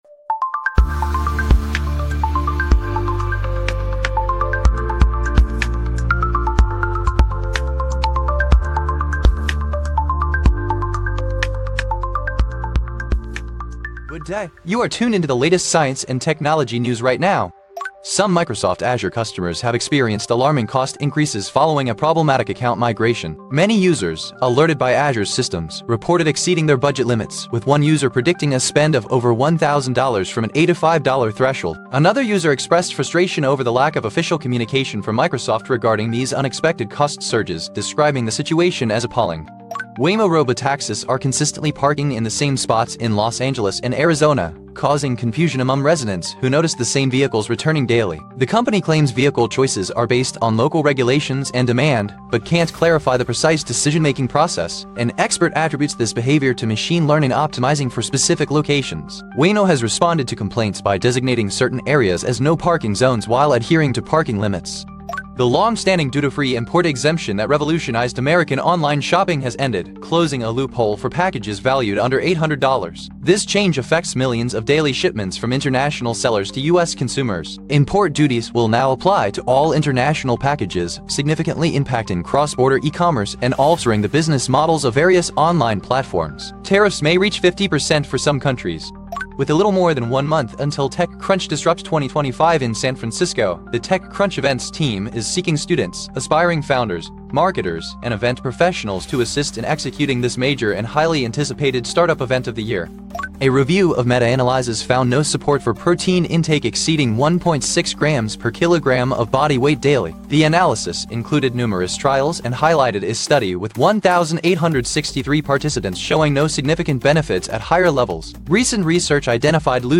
Eventually, I started by creating my own AI-powered online radio that broadcasts copyright-free music and what I consider “clean” news — free from bias and negativity.
• Jingles are also generated using TTS, with voices provided by ElevenLabs.